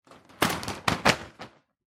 На этой странице собраны звуки традиционных японских ширм – редкие и атмосферные аудиозаписи.
Раскрыли створку ширмы